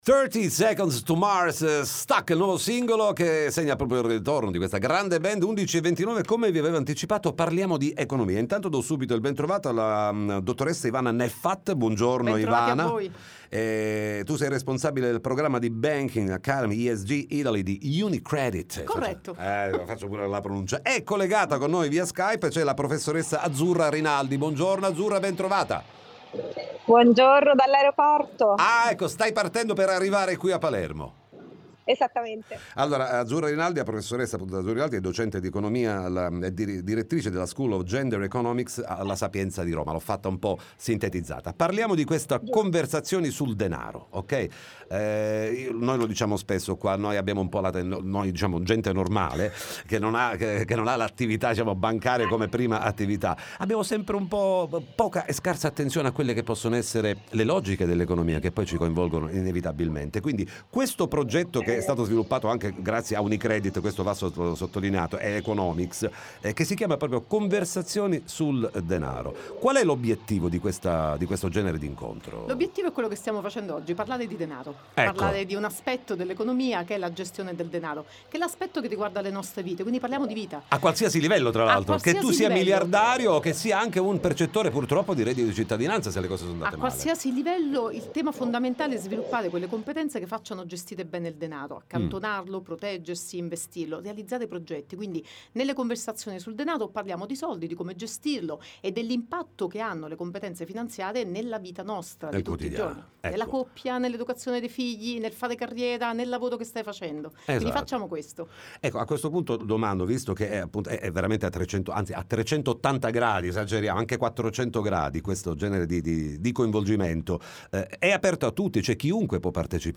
Time Magazine Intervista Unicredit